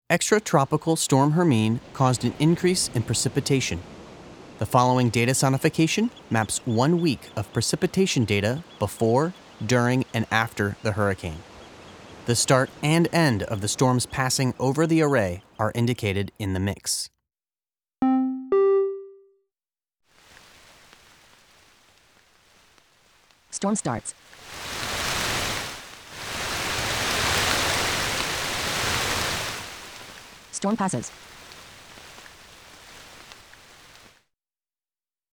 Storm-Hermine-Audio-Display-6-Precipitation-Sonification.mp3